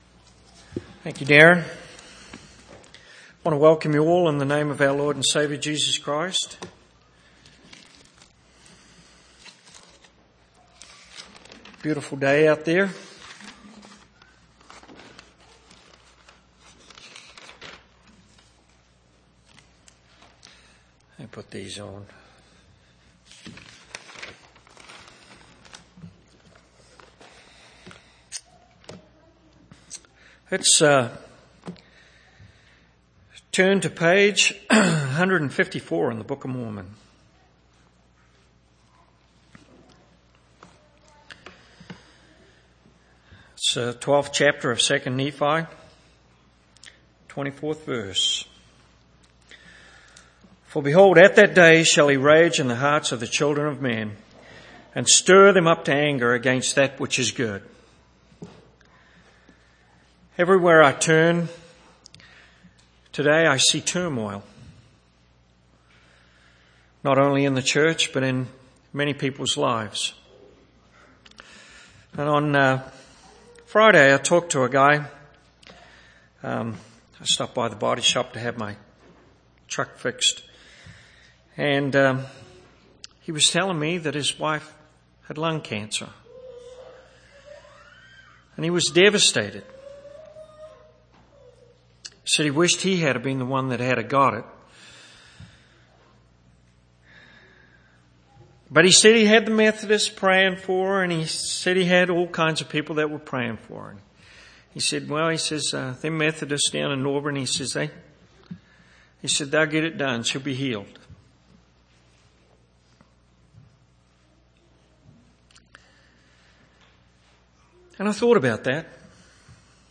11/14/2010 Location: Temple Lot Local Event